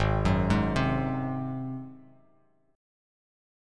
G#7 Chord
Listen to G#7 strummed